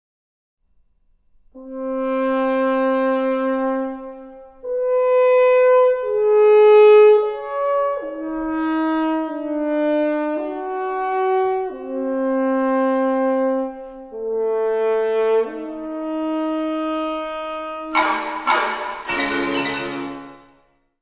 : stereo; 12 cm + folheto
piano
trompa
xilorimba
glockenspiel
Área:  Música Clássica